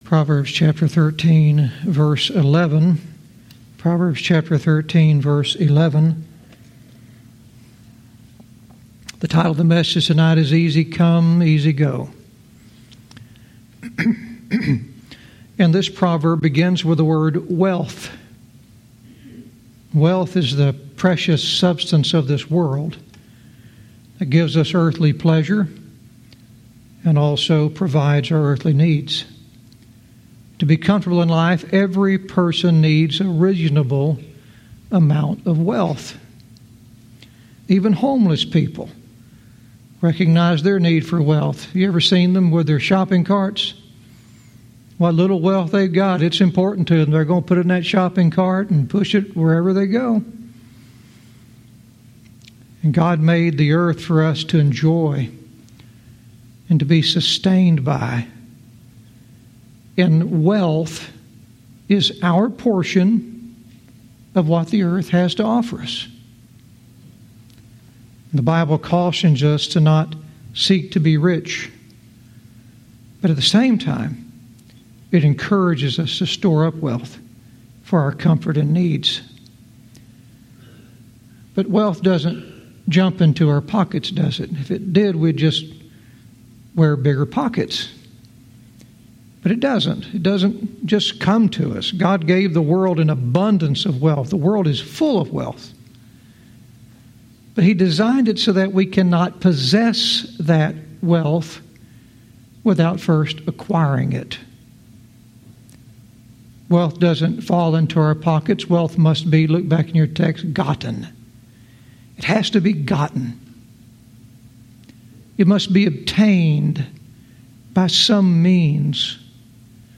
Verse by verse teaching - Proverbs 13:11 "Easy Come, Easy Go"